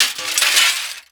GLASS_Window_Break_13_mono.wav